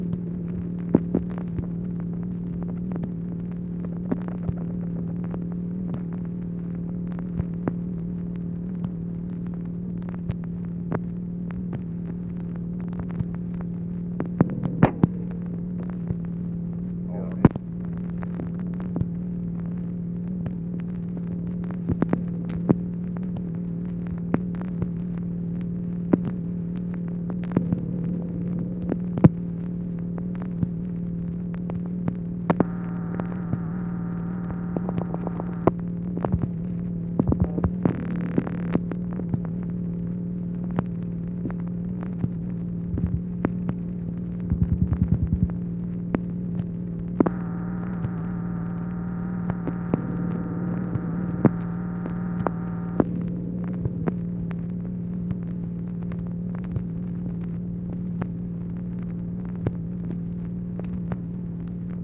OFFICE NOISE, January 7, 1964
Secret White House Tapes | Lyndon B. Johnson Presidency